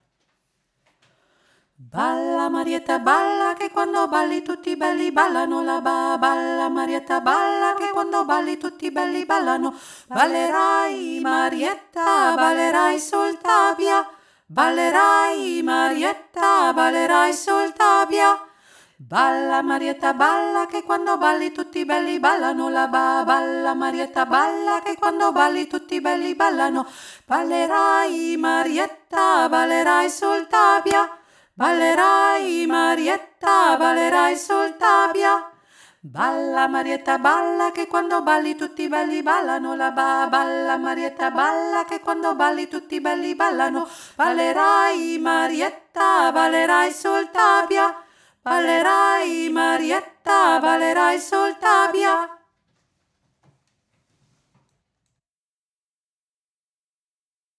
Balla Marietta italienisches Tanzlied
Zweistimmig
marietta-zweistimmig.mp3